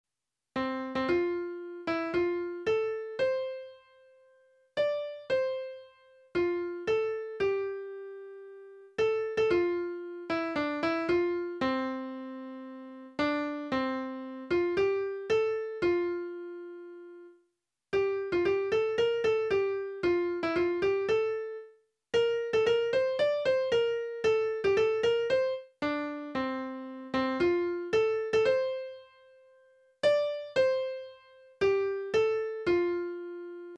東桜島（ひがしさくらじま）小学校の校歌